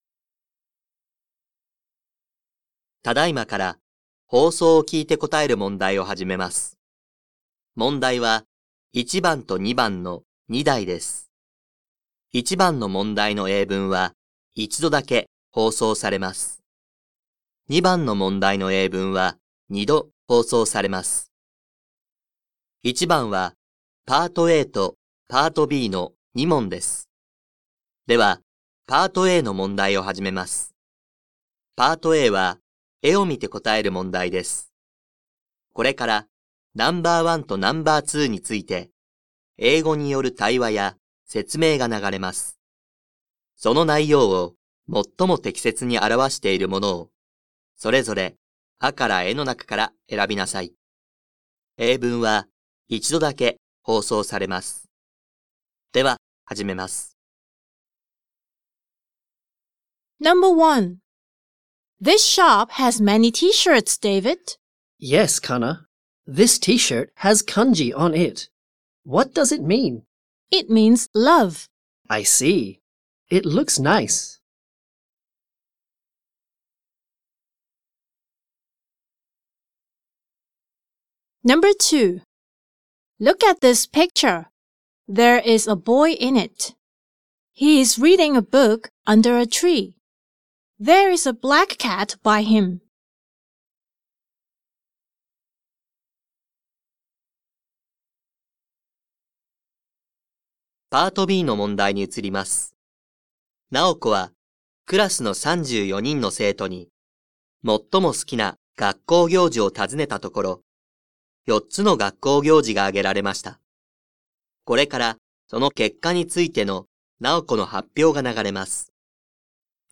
2025年度３年９号英語のリスニングテストの音声